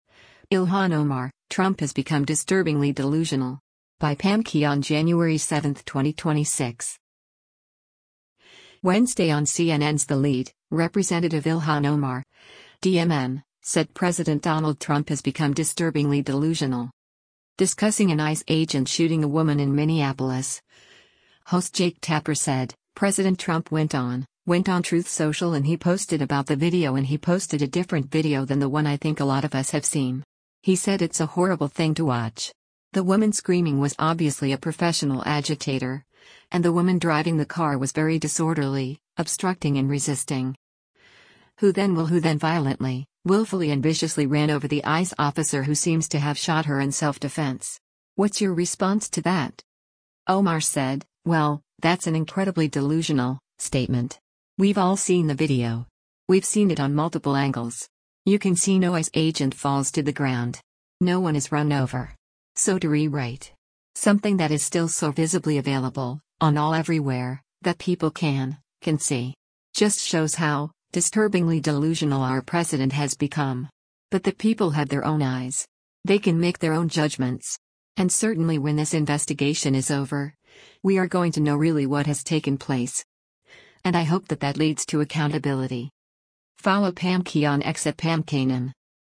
Wednesday on CNN’s “The Lead,” Rep. Ilhan Omar (D-MN) said President Donald Trump has become “disturbingly delusional.”